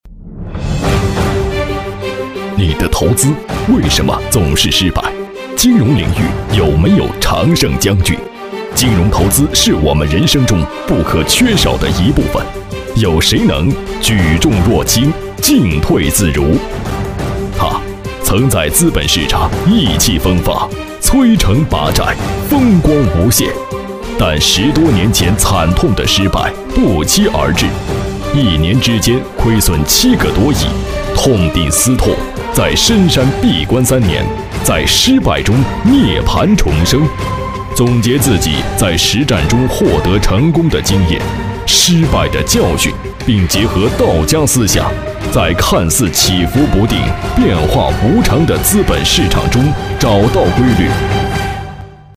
大气震撼 企业专题
磁性稳重男音。特点：激情有力，大气厚实。